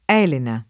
$lena] pers. f. — anche el$na alla greca, dapprima soprattutto per gallicismo, nei poeti dal ’300 al ’600: La reputò sì di bellezza piena, Che la prepose con seco ad Elena [la reput0 SSi ddi bell%ZZa pL$na, ke lla prep1Se kon S%ko ad el$na] (Boccaccio); Non fu bellezza viva Quella d’Elena argiva [non f2 bbell%ZZa v&va kU%lla d el$na arJ&va] (Chiabrera) — nei rimatori del ’200 e ’300, anche Alena [